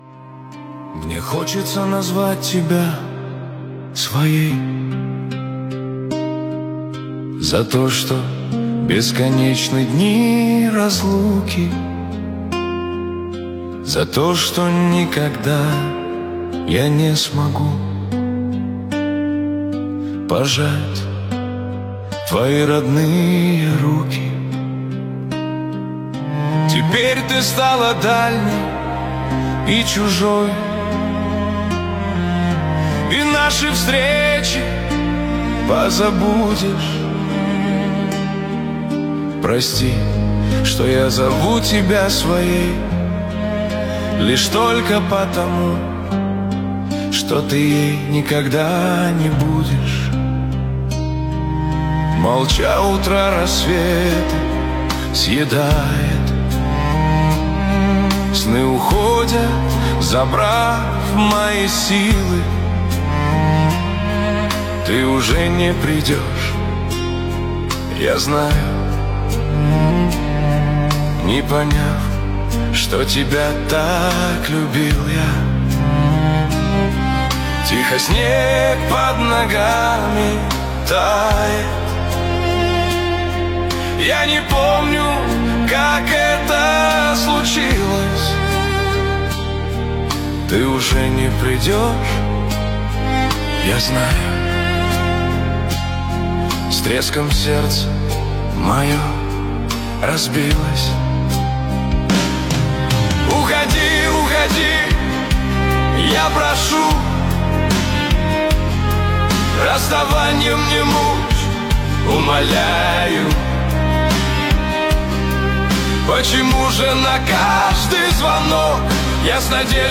Нейросеть (ИИ) -